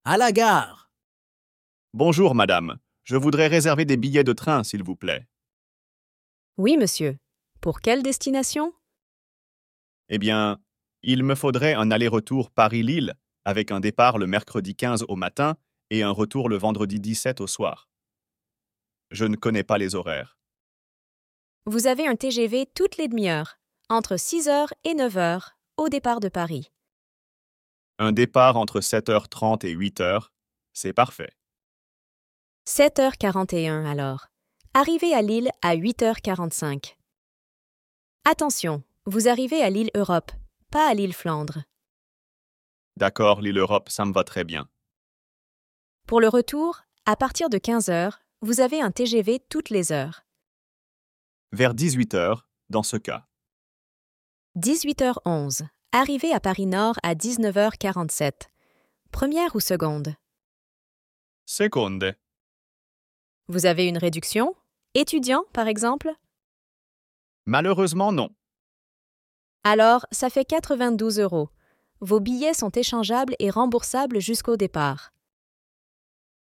Dialogue FLE à la gare